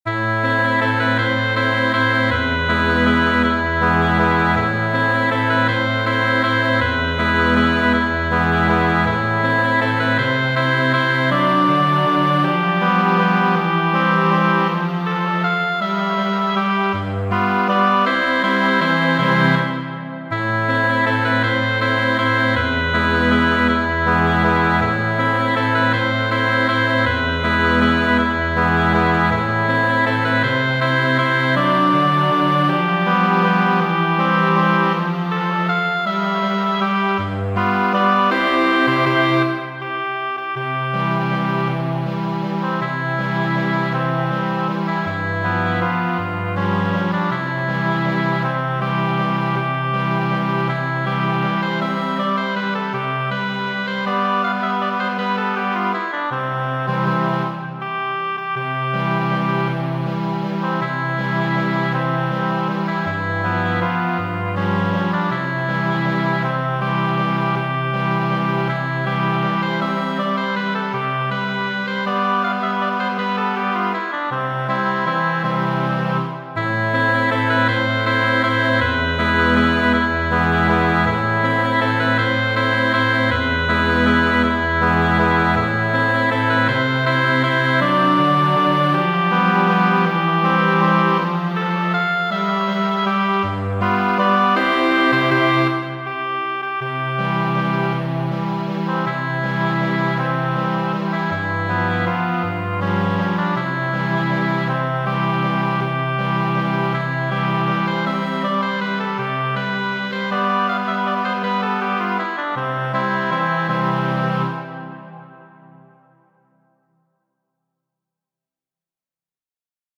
Muziko :
Atila valso de Antonjo Damas.